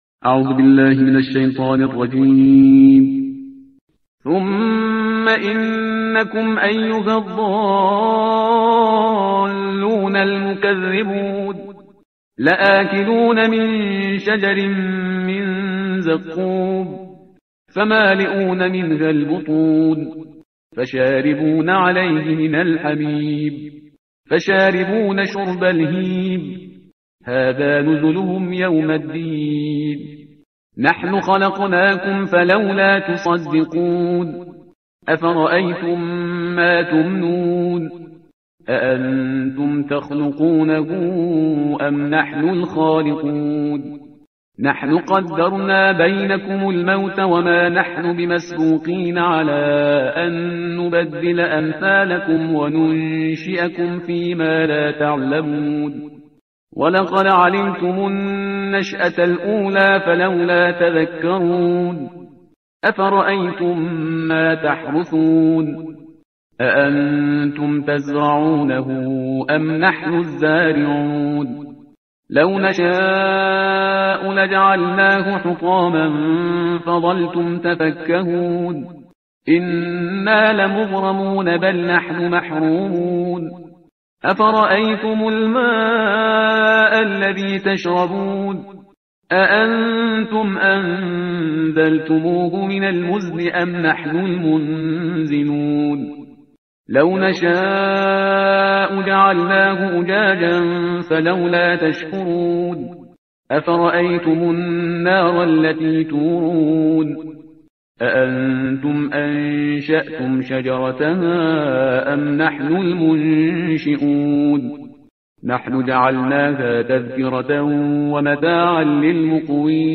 ترتیل صفحه 536 قرآن با صدای شهریار پرهیزگار